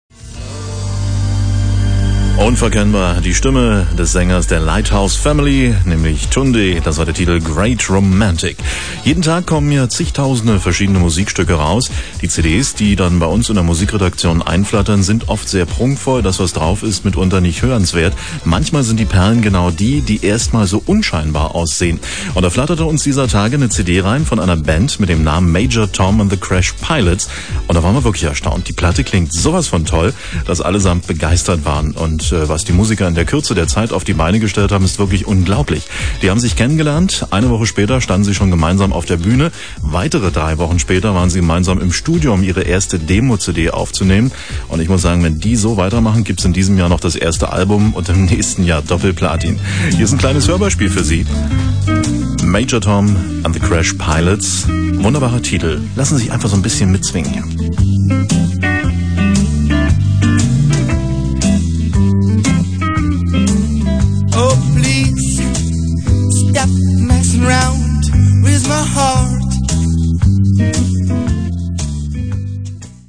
ein wunderbarer Swing
Lassen Sie sich einfach so ein bißchen mitswingen!